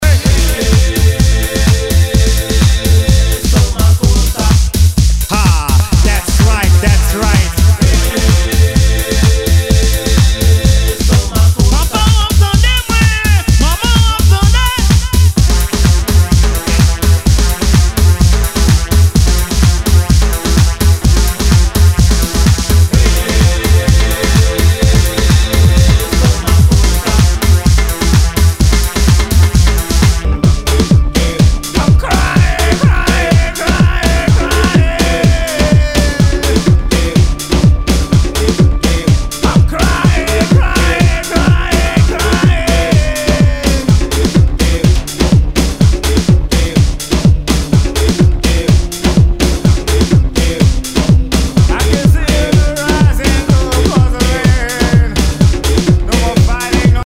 HOUSE/TECHNO/ELECTRO
トライバル・ハウス！